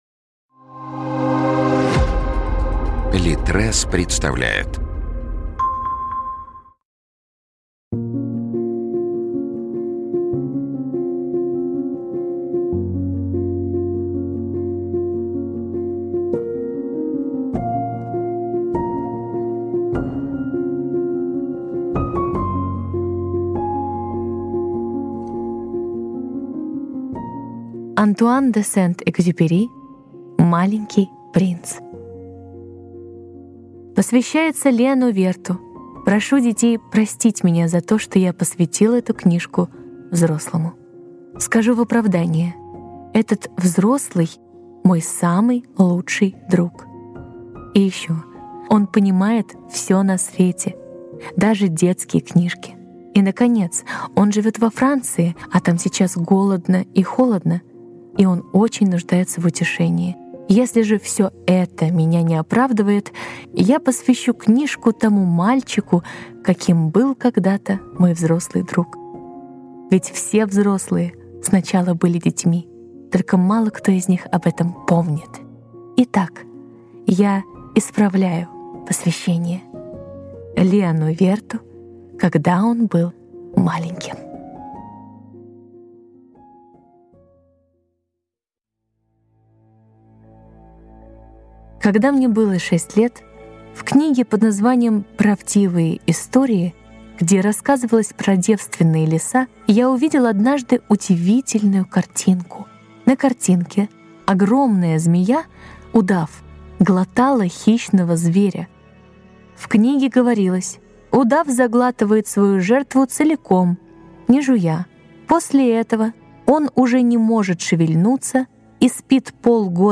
Аудиокнига Маленький принц | Библиотека аудиокниг
Aудиокнига Маленький принц Автор Антуан де Сент-Экзюпери Читает аудиокнигу Елизавета Арзамасова.